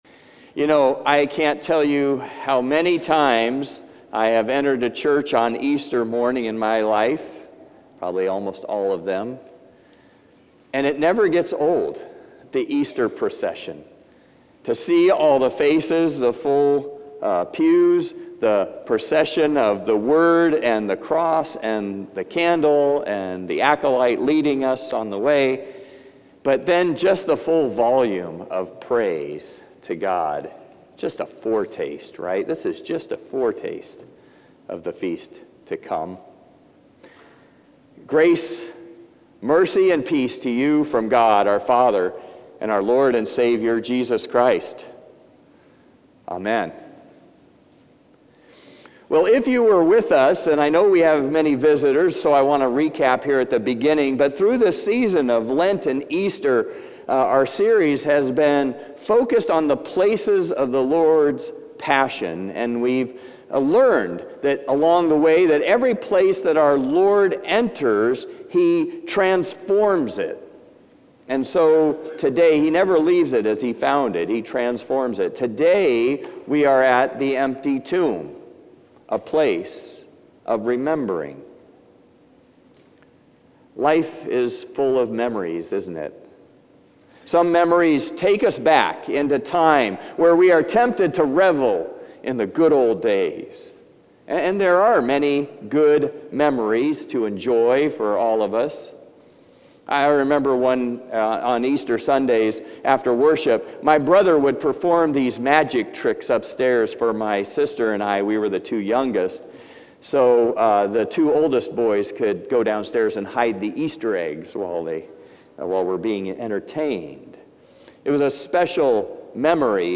Service Type: Traditional and Blended